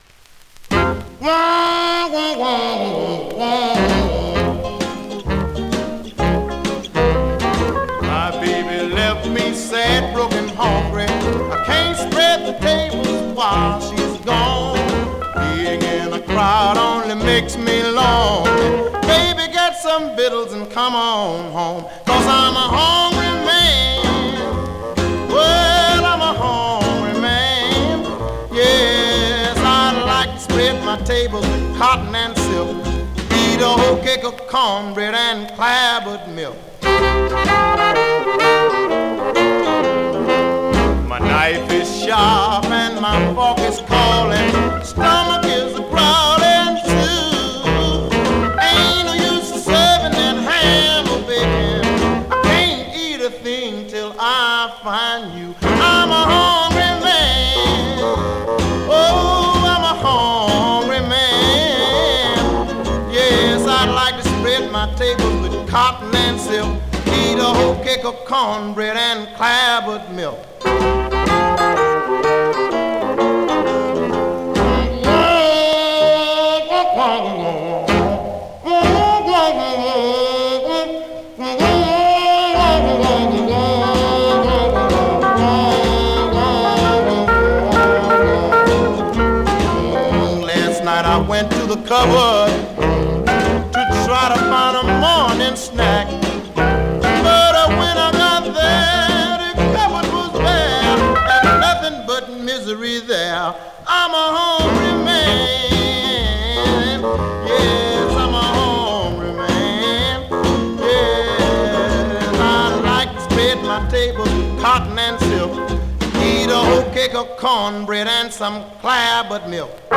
R&B, MOD, POPCORN